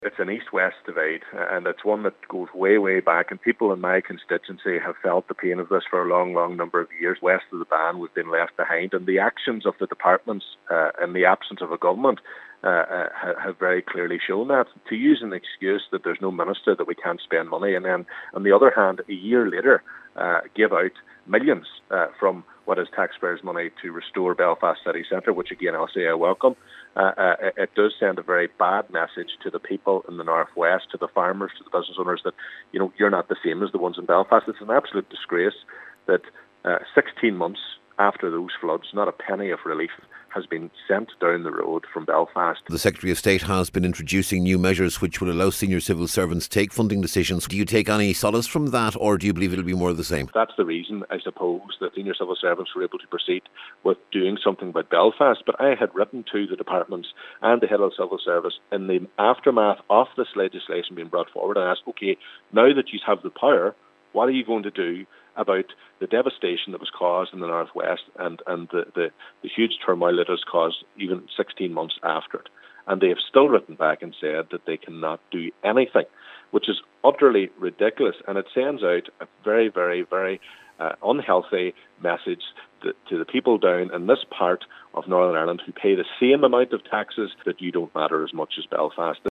Mr Mc Crossan says people in rural communities are still being victimised: